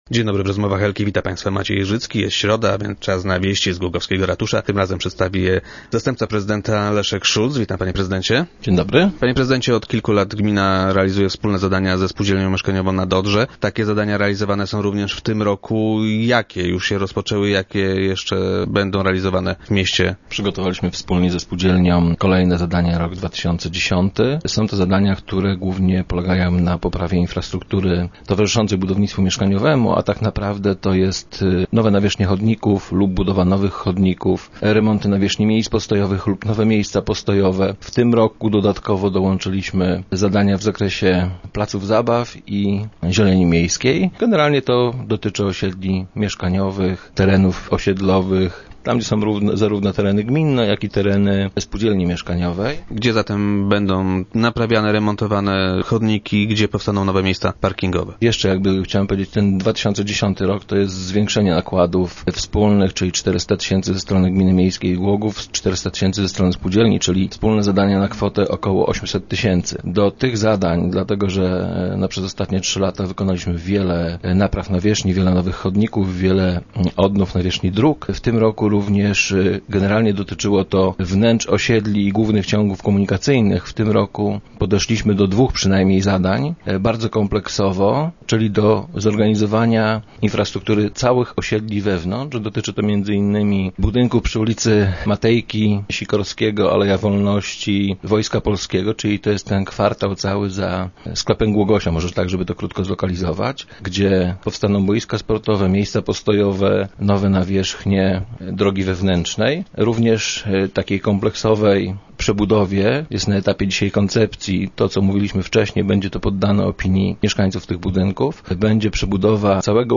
W tym roku dołączyliśmy także budowę nowych placów zabaw - informuje Leszek Szulc, zastępca prezydenta Głogowa, który był dziś gościem Rozmów Elki.